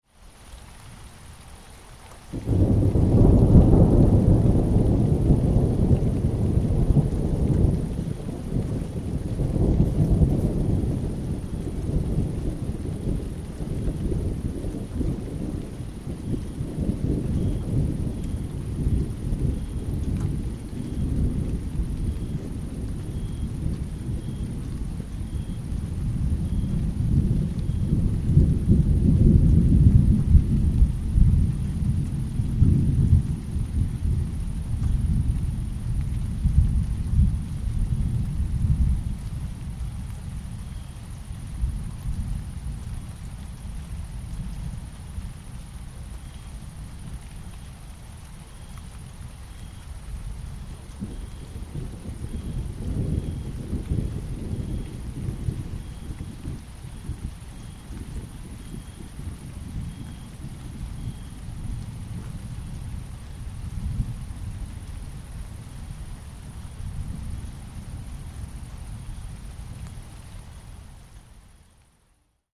دانلود صدای باران و رعد و برق از ساعد نیوز با لینک مستقیم و کیفیت بالا
جلوه های صوتی
برچسب: دانلود آهنگ های افکت صوتی طبیعت و محیط